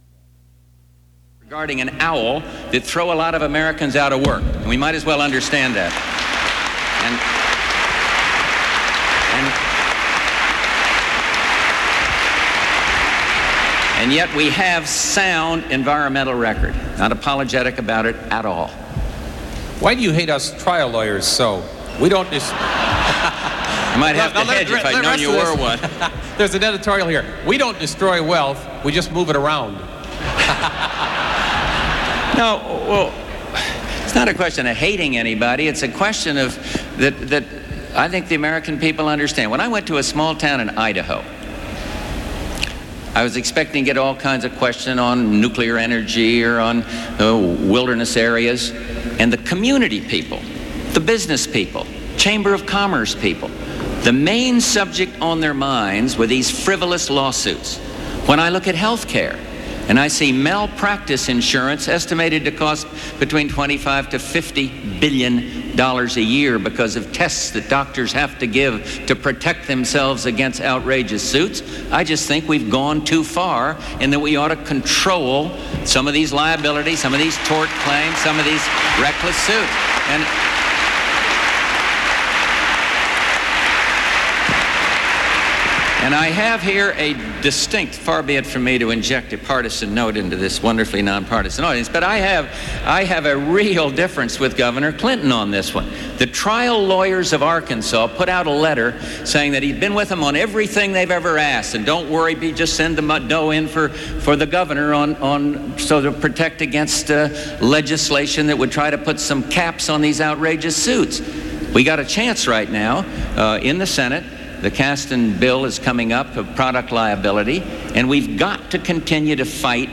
George Bush answers audience questions after delivering his economic message to the Economic Club of Detroit